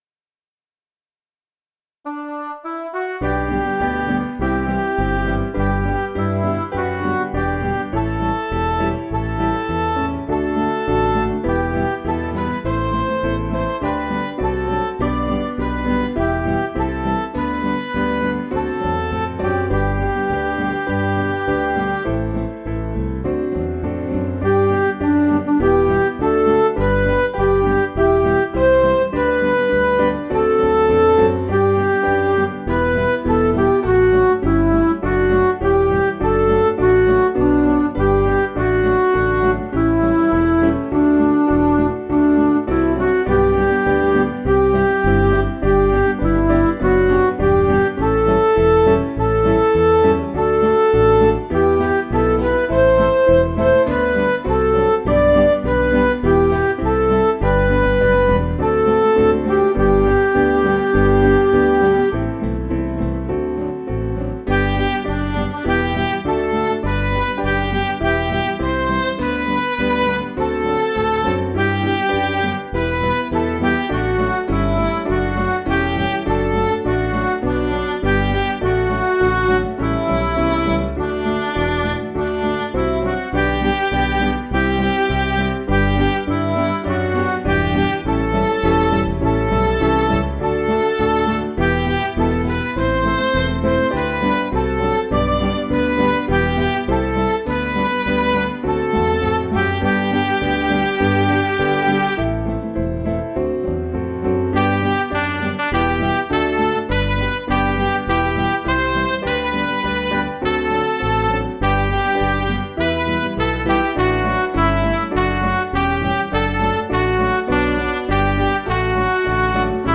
Simple Piano
Midi